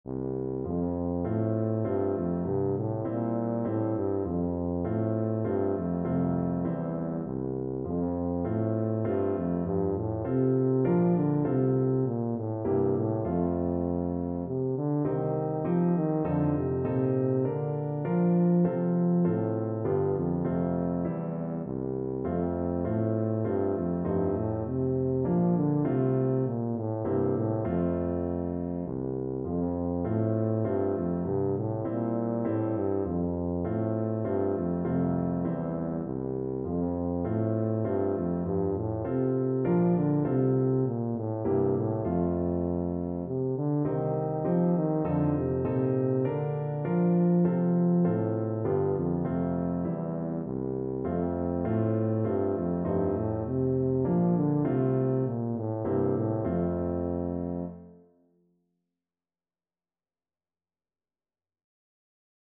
Tuba
Traditional Music of unknown author.
3/4 (View more 3/4 Music)
C3-F4
F major (Sounding Pitch) (View more F major Music for Tuba )
Flowing
Scottish